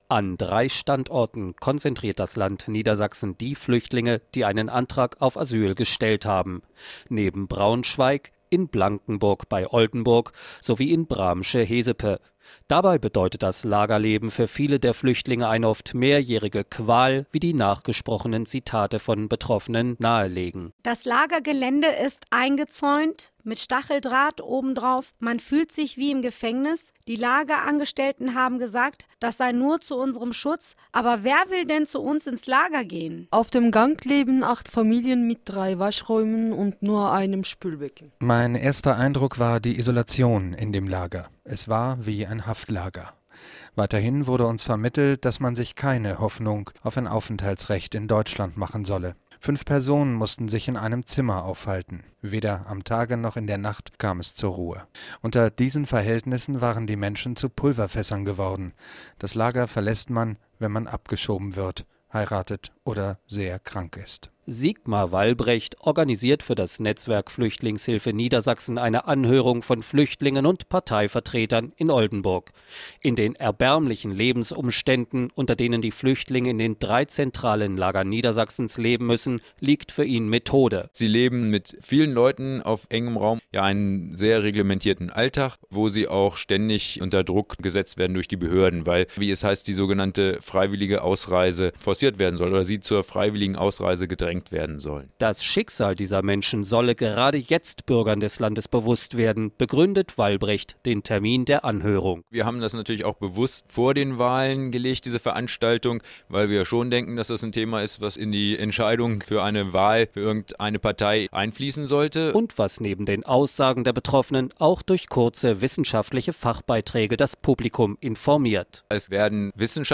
Radiobeitrag zur Anhörung "Leben in Lagern"